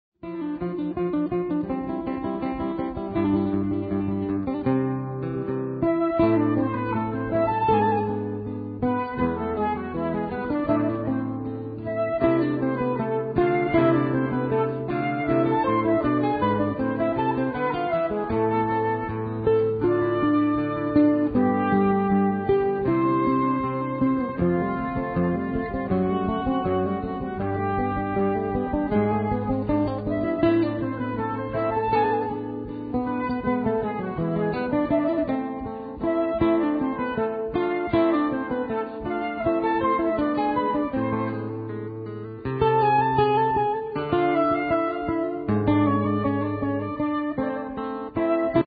Muramatsu flute
Guitars
Harpsichord